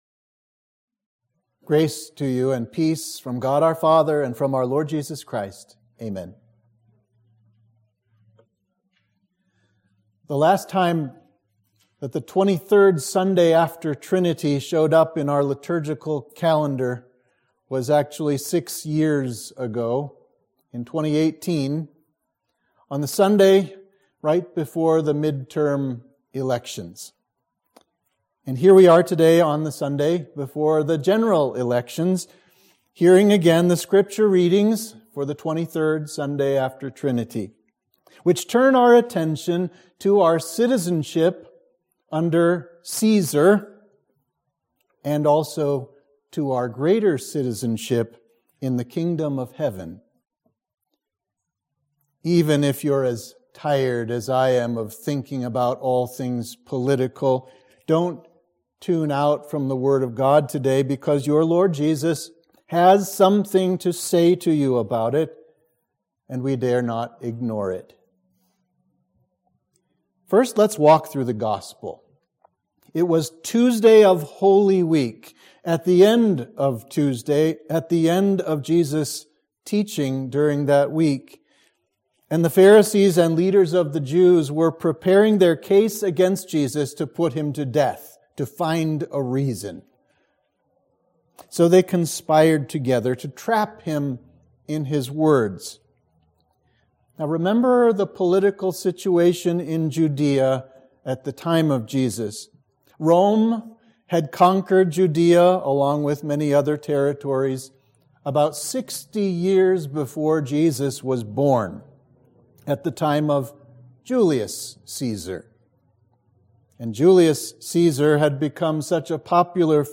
Sermon for Trinity 23